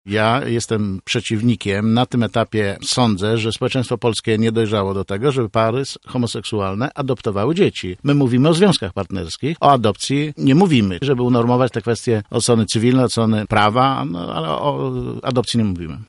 Jacek Czerniak, przewodniczący rady wojewódzkiej Sojuszu Lewicy Demokratycznej i zarazem kandydat numer 1 na liście Lewicy do Sejmu w Porannej Rozmowie Radia Centrum zaprzeczył jakoby jego ugrupowanie było za zalegalizowaniem adopcji dzieci przez małżeństwa homoseksualne.